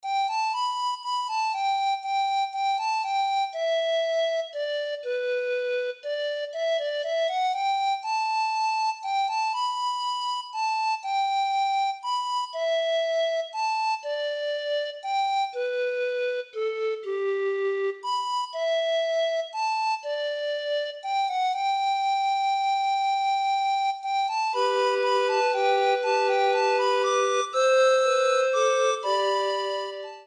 Variations in Trio